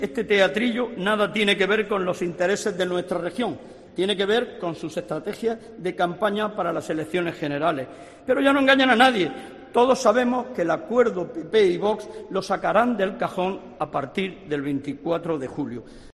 José Vélez, portavoz del PSRM-PSOE en la Asamblea Regional